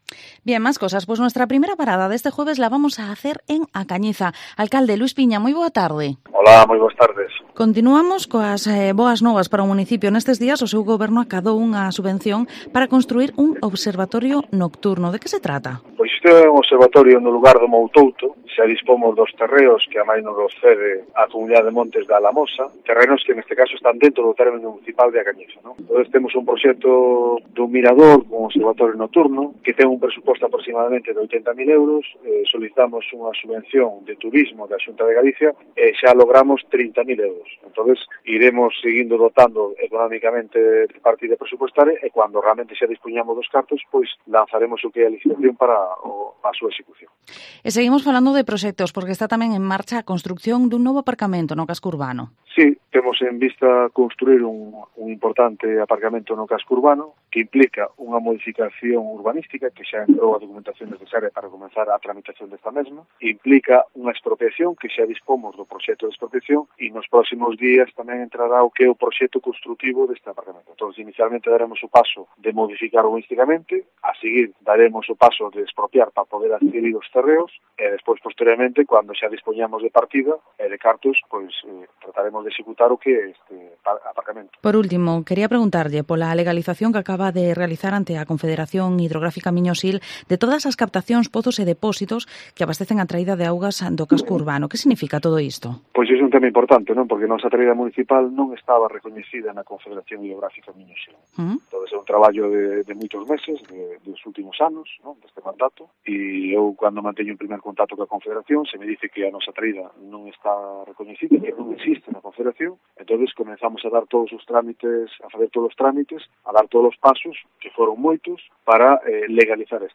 Entrevista al Alcalde de A Cañiza, Luis Piña